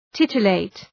Προφορά
{‘tıtə,leıt} (Ρήμα) ● γαργαλίζω ● γαργαλάω